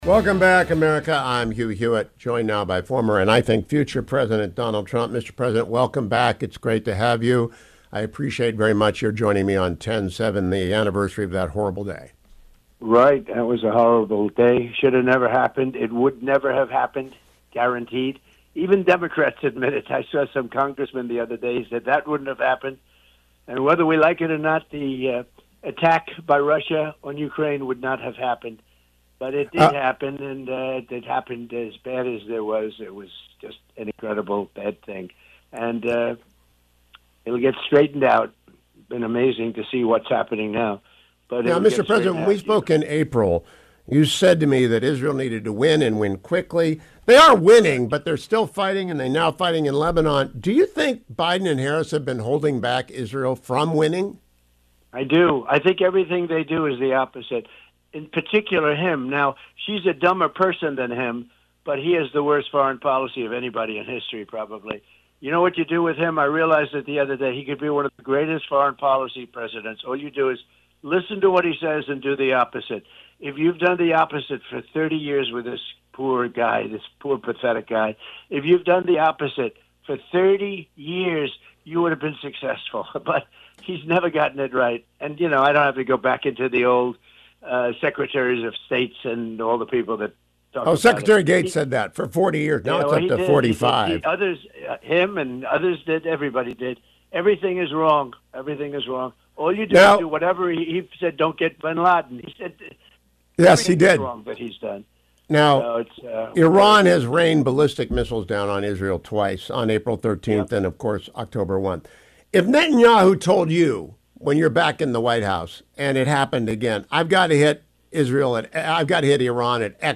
Former President Trump joined me this morning: